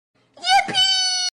Yippie Sound Button: Unblocked Meme Soundboard